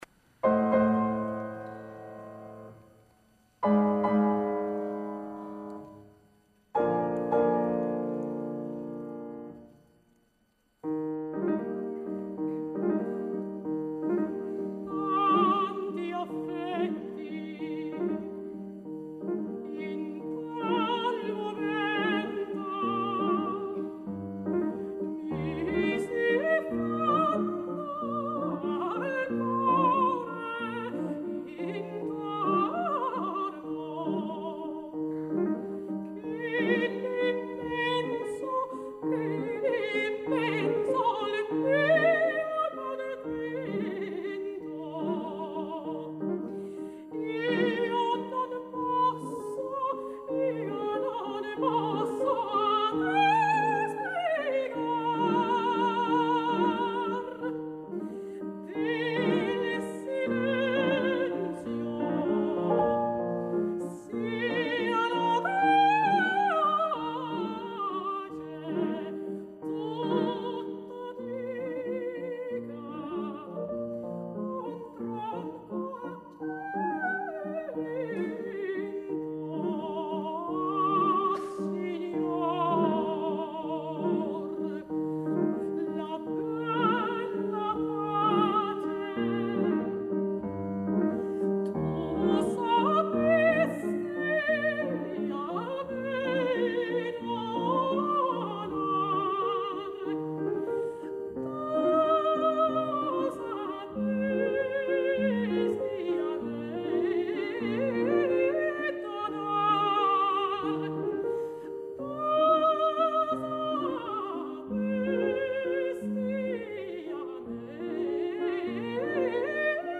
La seva veu gran, a hores d’ara i malgrat els seus 24 anys, ja extensa i penetrant, està dotada d’un timbre, una personalitat i un caràcter, fora del normal en una cantant tan jove.
Julia Lezhneva, soprano
piano
Elisabeth Murdoch Hall, Melbourne Recital Centre 19 de març de 2014
Además no cae en la tentación de añadir agudos y sobreagudos. Las escalas cromáticas ascendentes son impecables, las descendentes adolecen de una zona grave muy débil, en un aria plagada de graves, pero dada su juventud eso es algo que estoy seguro que irá mejorando.